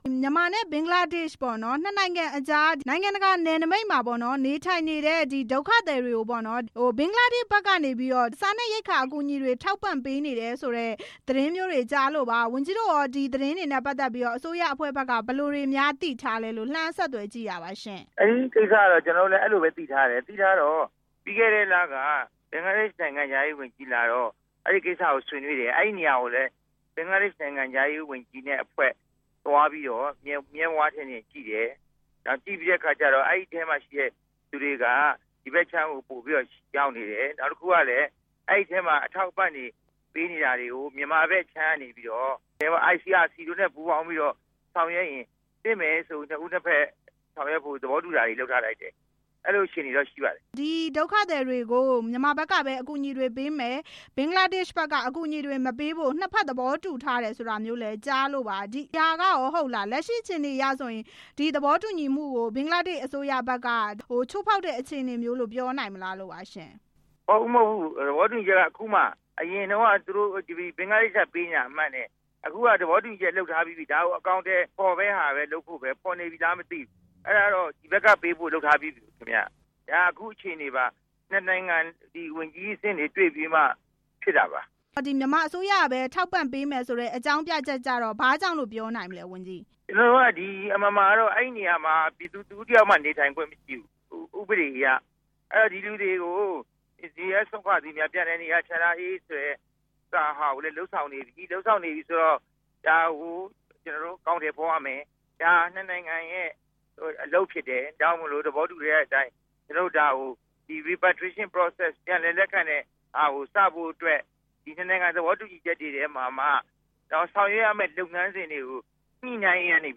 ဒေါက်တာဝင်းမြတ်အေးနဲ့ ဆက်သွယ်မေးမြန်းချက်